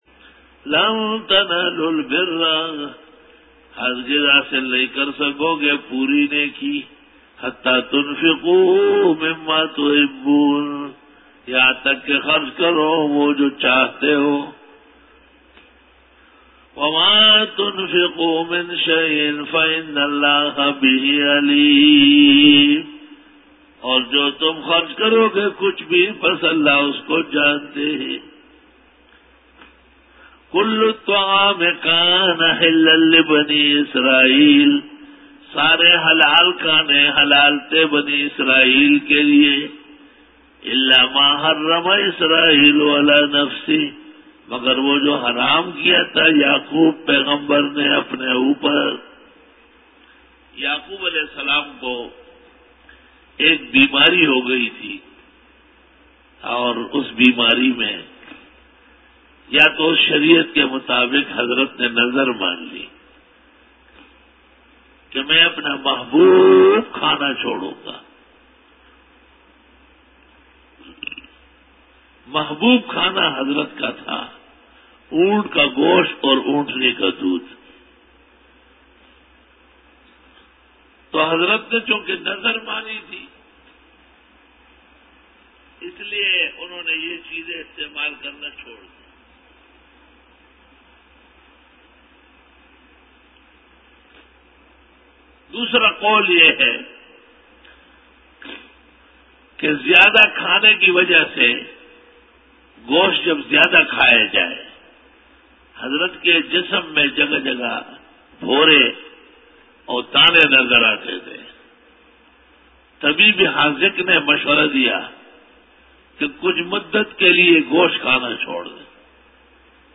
Dora-e-Tafseer 2011